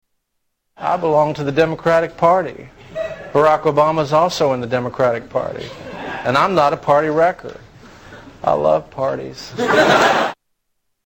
Tags: Comedians Darrell Hammond Darrell Hammond Impressions SNL Television